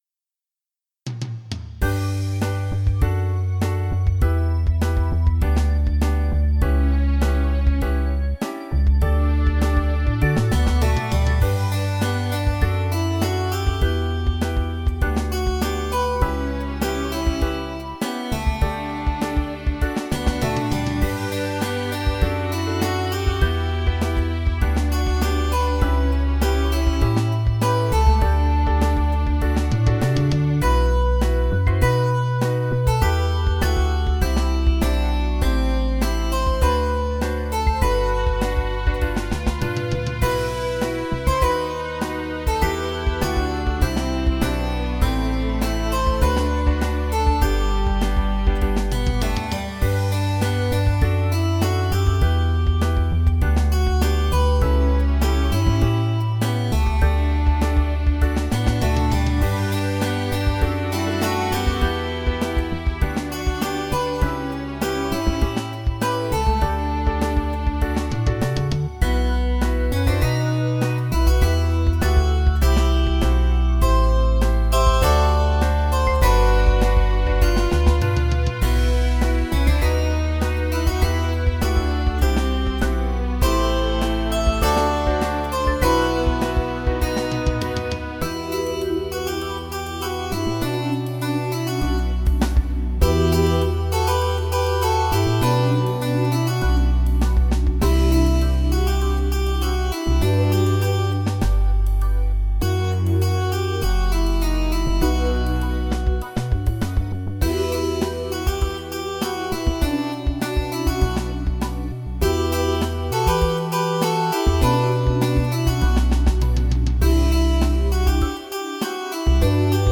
version instrumentale